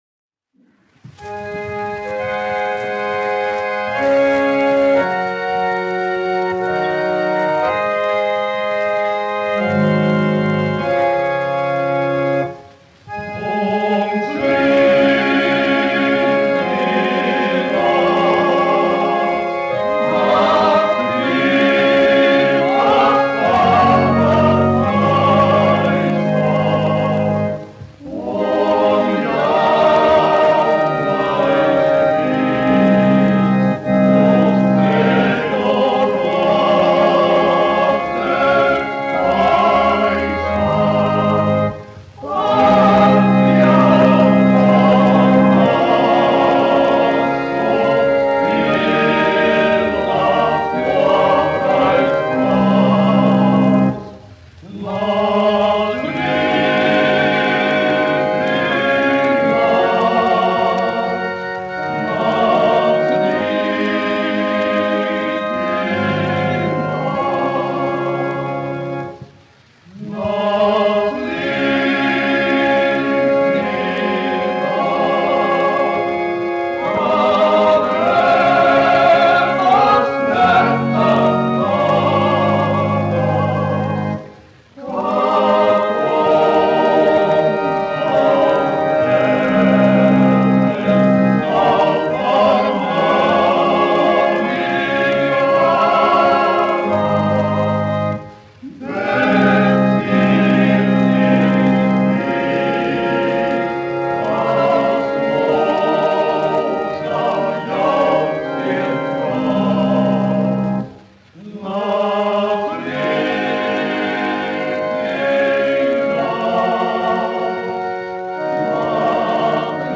1 skpl. : analogs, 78 apgr/min, mono ; 25 cm
Korāļi
Vokālie seksteti
Latvijas vēsturiskie šellaka skaņuplašu ieraksti (Kolekcija)